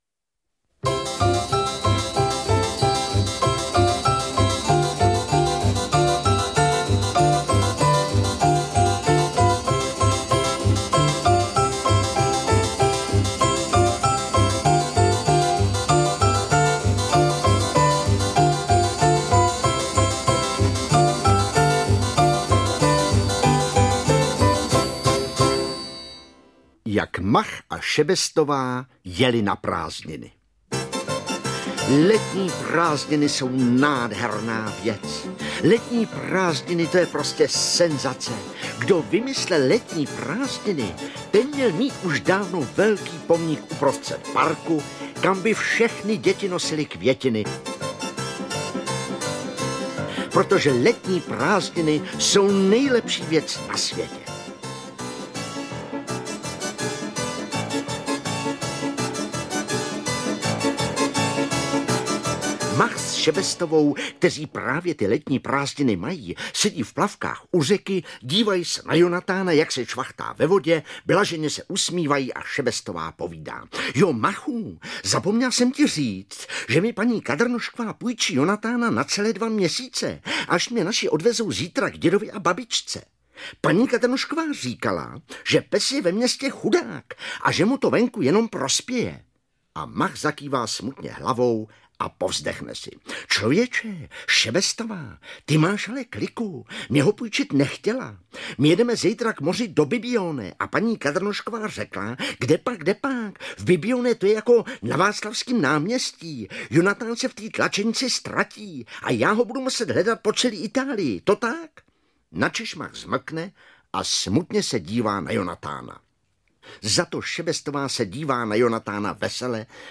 Interpret:  Petr Nárožný